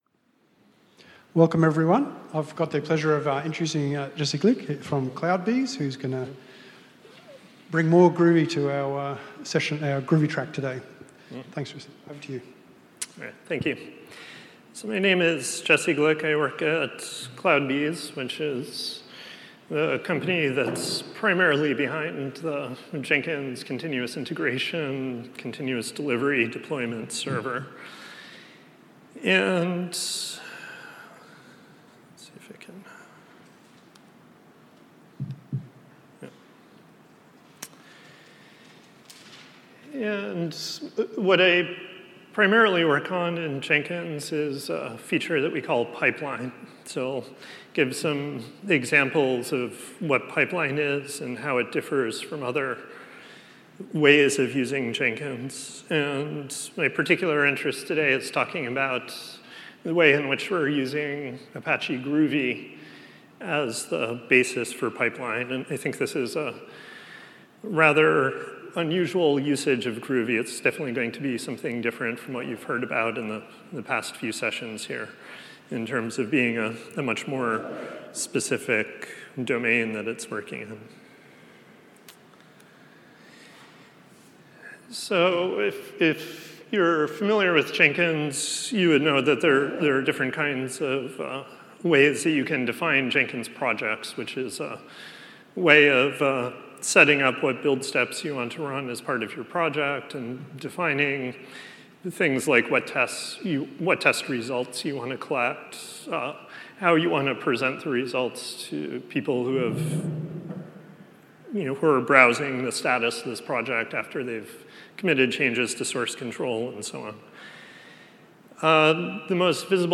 ApacheCon Seville 2016